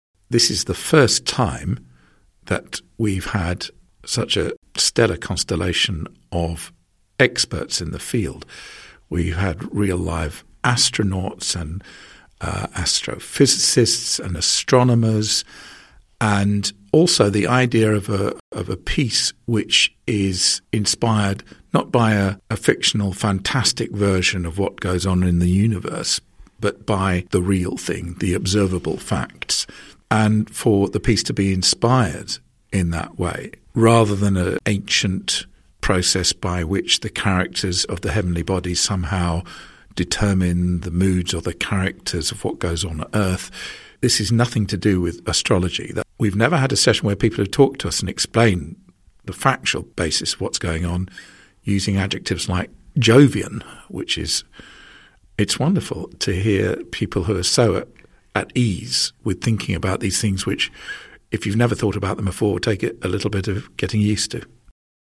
audio quote